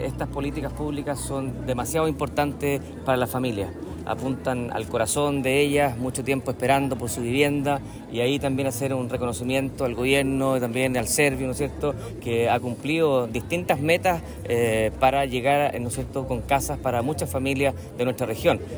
SERVIU_Parque-Bellavista-4_gobernador-Maule.mp3